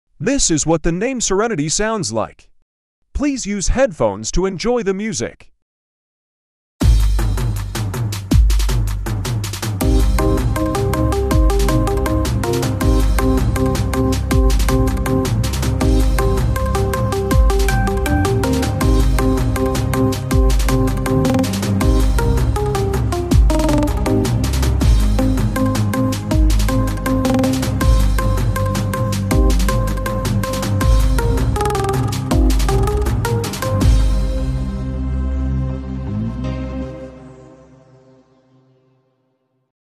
midi art